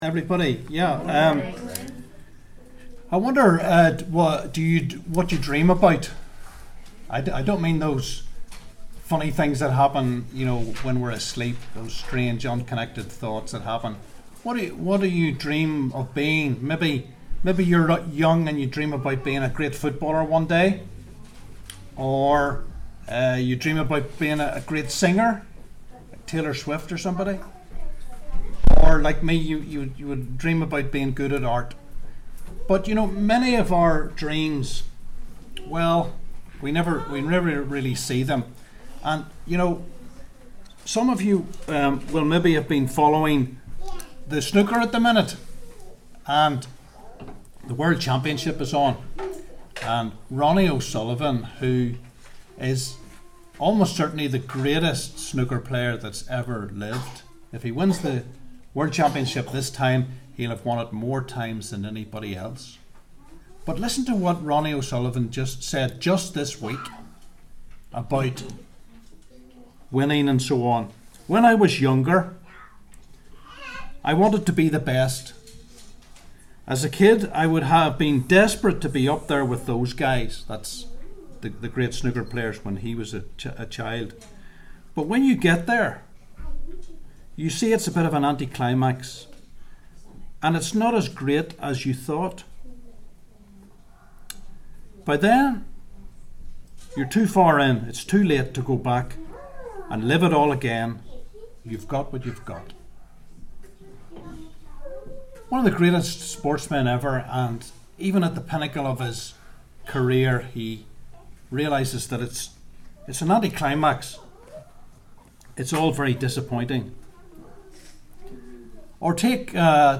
Preacher
Luke 21:1-4 Service Type: 11am Topics: giving , Surrender « Beware